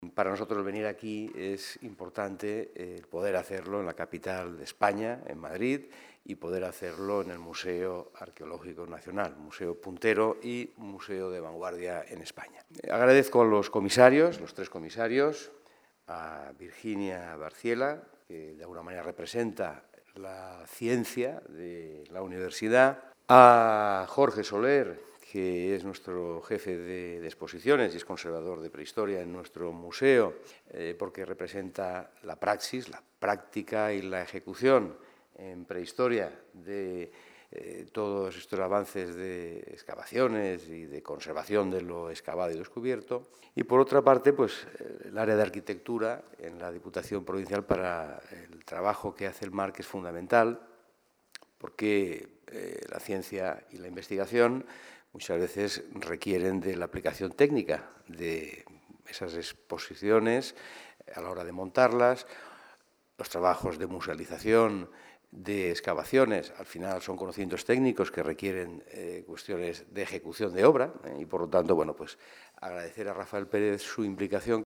El diputado de Cultura, César Augusto Asencio, ha presentado esta mañana todos los detalles de esta nueva propuesta que “conducirá al visitante por un extraordinario recorrido a través de nuestro Primer Arte, desde Altamira hasta los abrigos del arte macroesquemático del Pla de Petracos en Castells. La Diputación de Alicante apuesta de nuevo por un original y sólido proyecto que reunirá piezas únicas en una muestra inédita”.